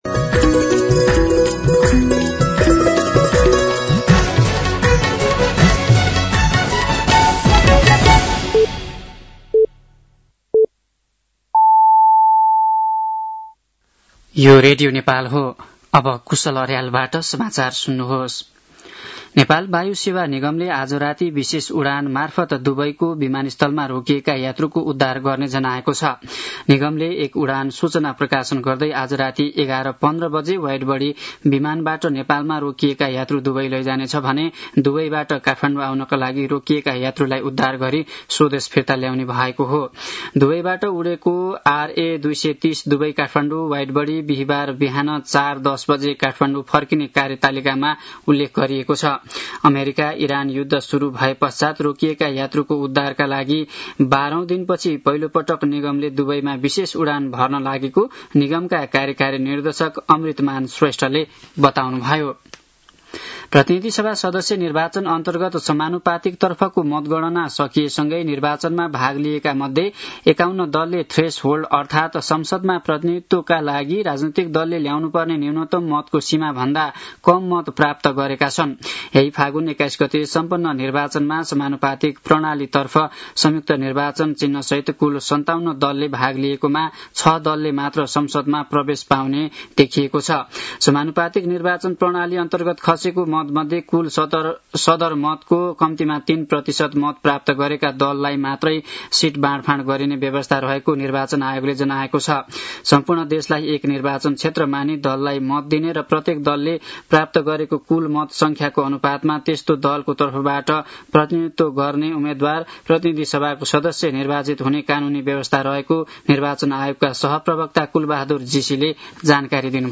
साँझ ५ बजेको नेपाली समाचार : २७ फागुन , २०८२
5-pm-news-11-27.mp3